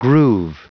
Prononciation du mot groove en anglais (fichier audio)
Prononciation du mot : groove